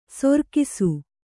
♪ sorkisu